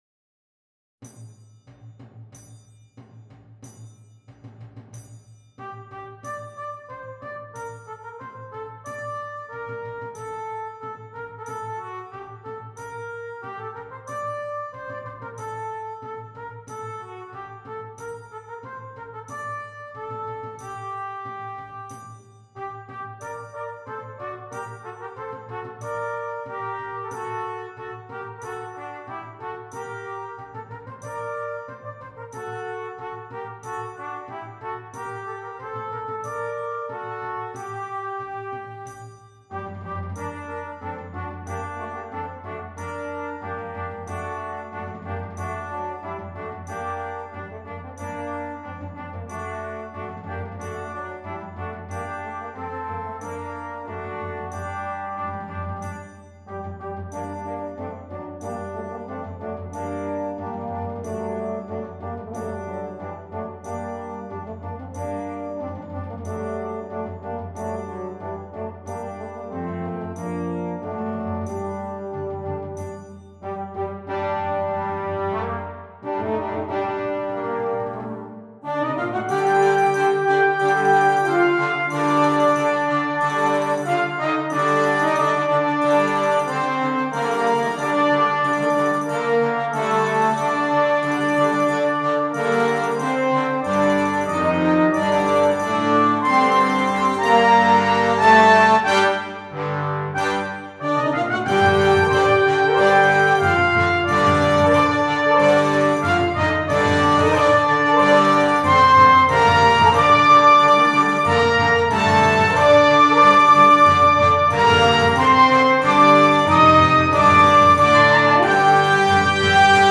Brass Band
Traditional